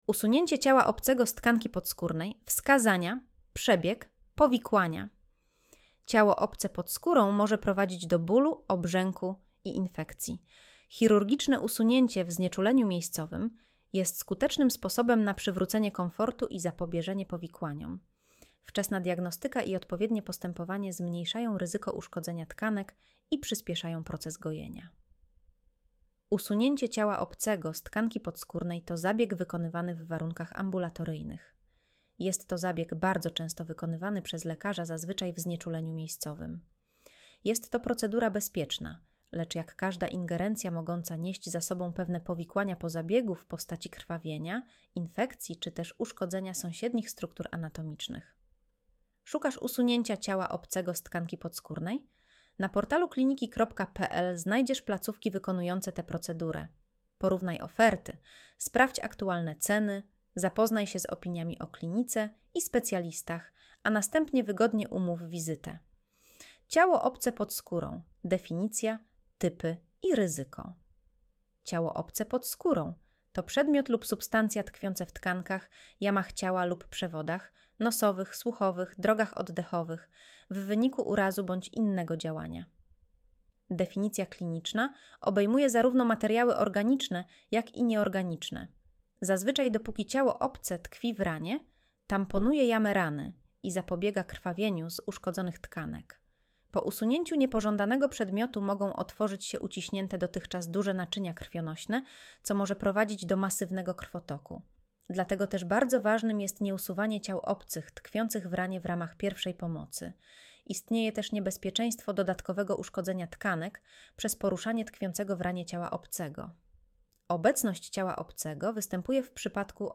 Słuchaj artykułu Audio wygenerowane przez AI, może zawierać błędy